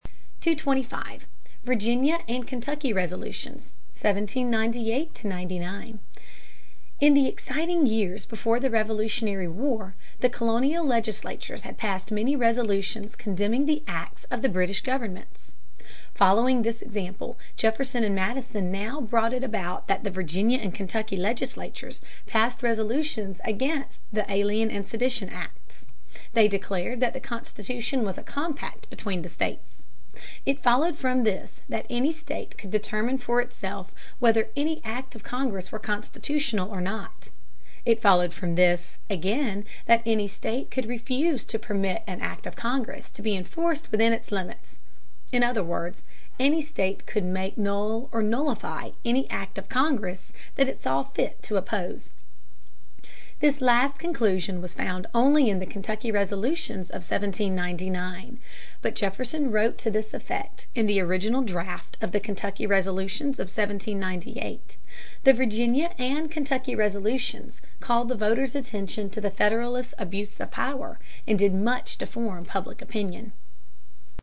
在线英语听力室美国学生历史 第88期:最后一个联邦党政府(6)的听力文件下载,这套书是一本很好的英语读本，采用双语形式，配合英文朗读，对提升英语水平一定更有帮助。